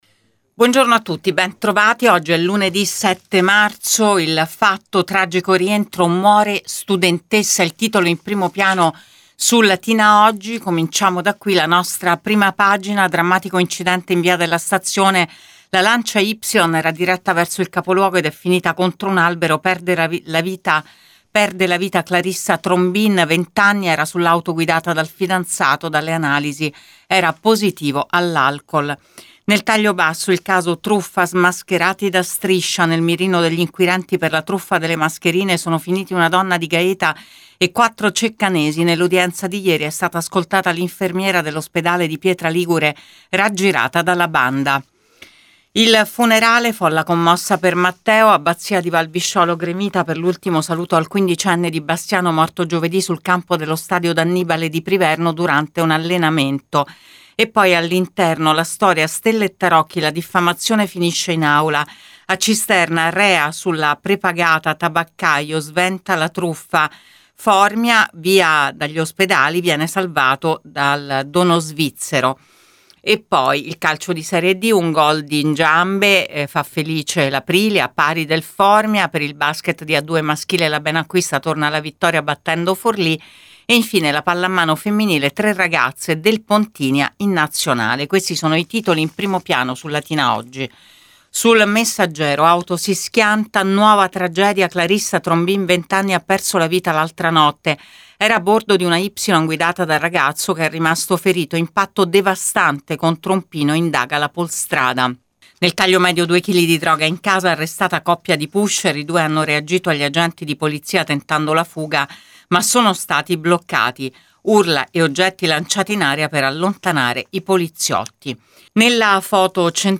LATINA – Qui trovate Prima Pagina, ora solo in versione web, per dare uno sguardo ai titoli di Latina Editoriale Oggi e Il Messaggero Latina. (audio dopo la pubblicità)